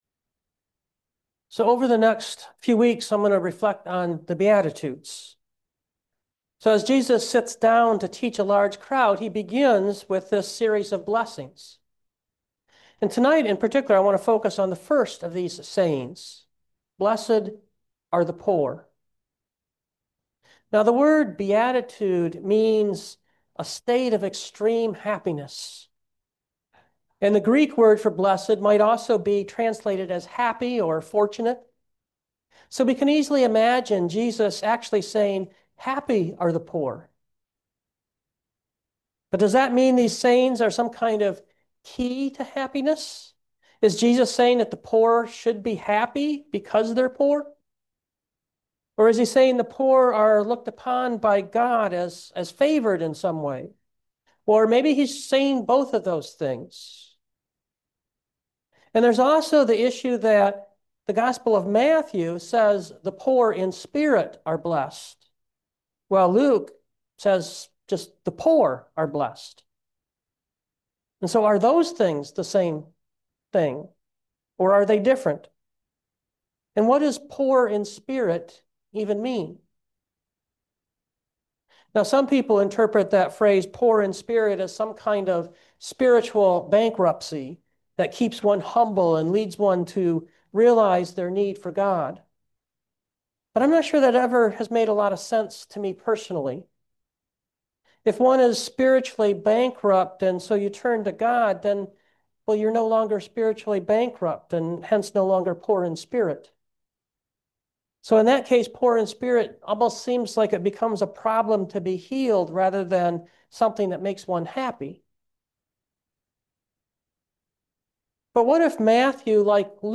2026 Blessed Are The Poor Preacher